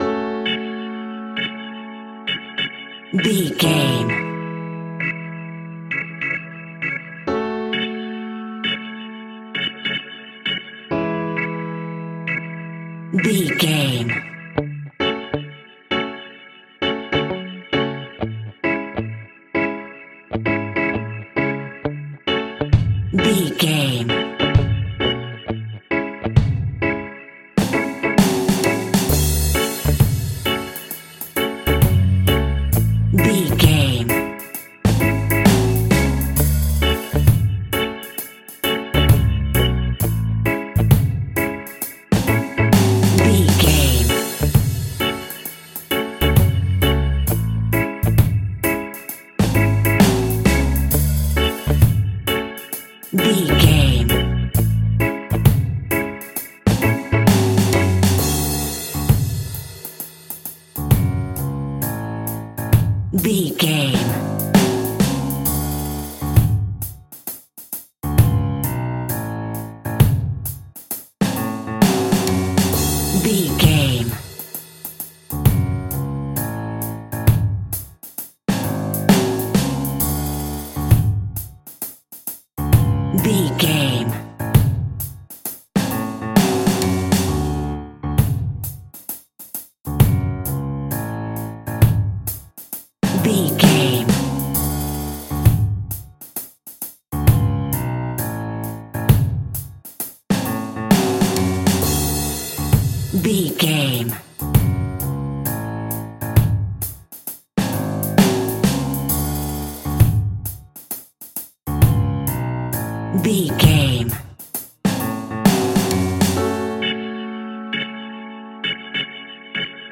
A chilled and relaxed piece of smooth reggae music!
Aeolian/Minor
F#
off beat
drums
skank guitar
hammond organ
percussion
horns